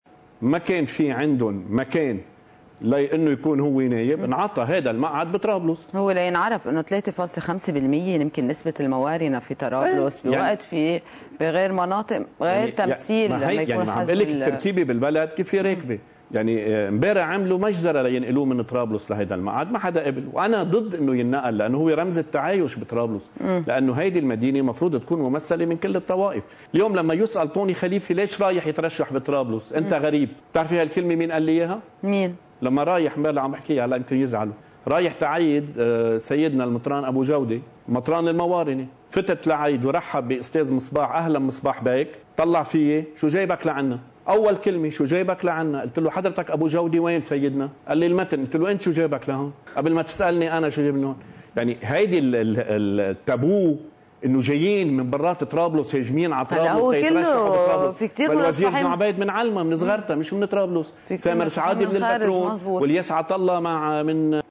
مقتطف من حديث الإعلامي طوني خليفة، المرشّح عن المقعد الماروني في طرابلس، لقناة “الجديد”: (21 نيسان 2018)